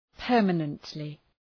Προφορά
{‘pɜ:rmənəntlı} (Επίρρημα) ● μόνιμα